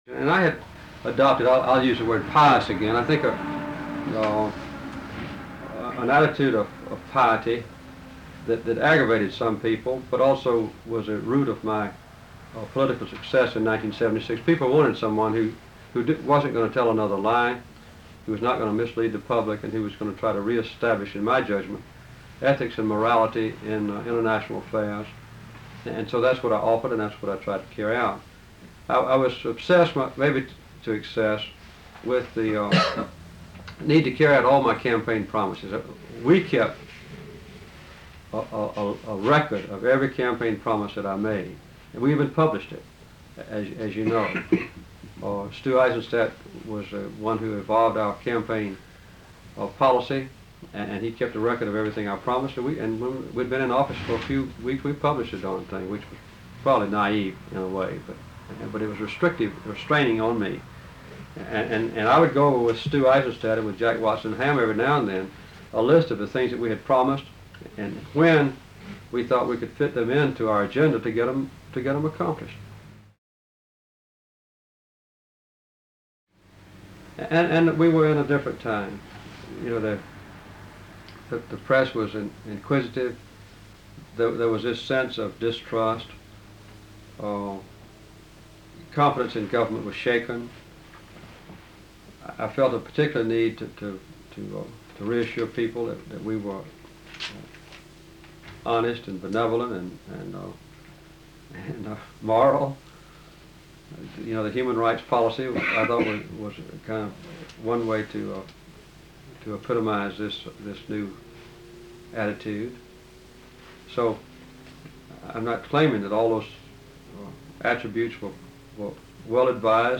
Carter also reflected on the importance of following through on campaign promises—both popular and unpopular—that he believed were right. Date: November 29, 1982 Participants Jimmy Carter Associated Resources Jimmy Carter Oral History The Jimmy Carter Presidential Oral History Audio File Transcript